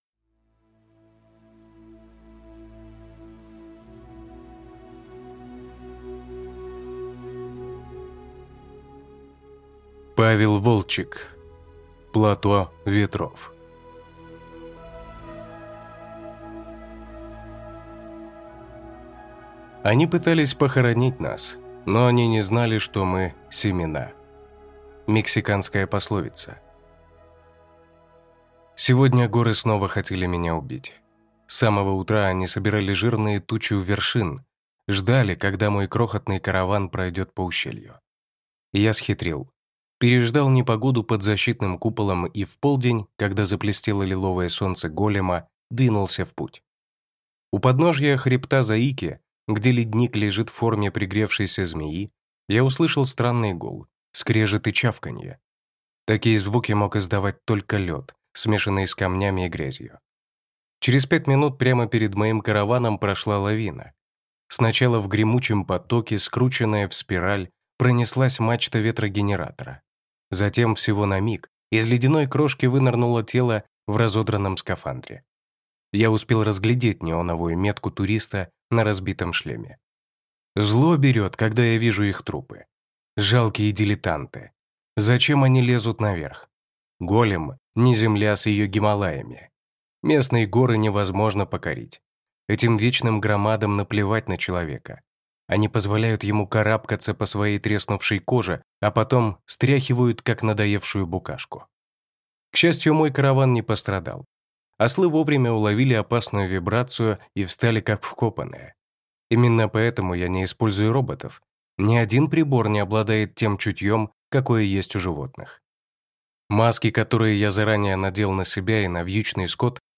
Аудиокнига Плато Ветров | Библиотека аудиокниг